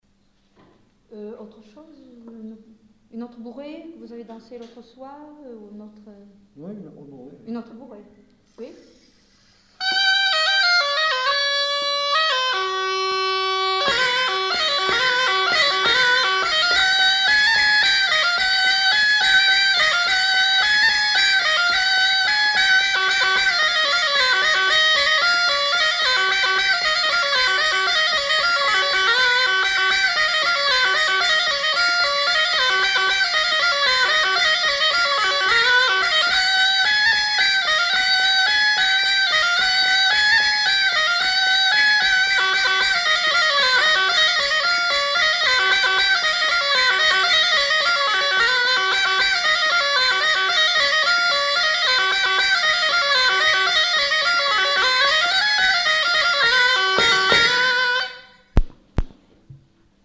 Bourrée
Lieu : Marvejols
Genre : morceau instrumental
Instrument de musique : cabrette ; grelot
Danse : bourrée